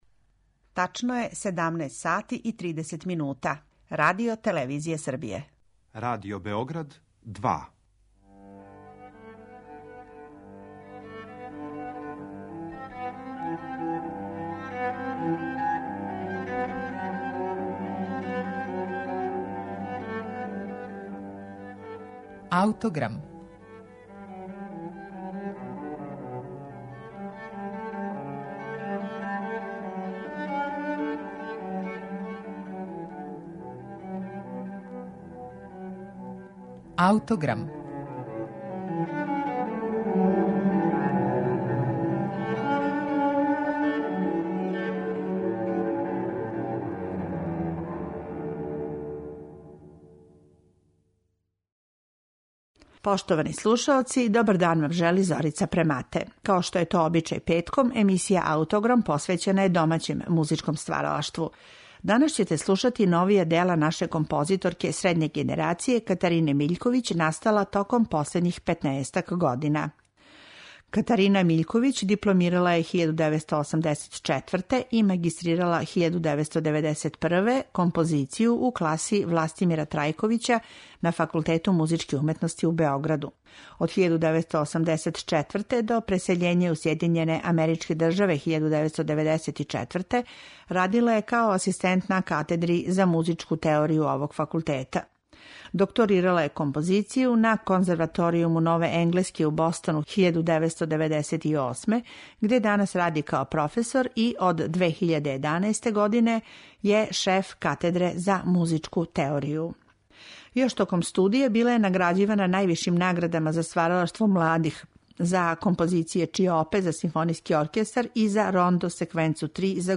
клавир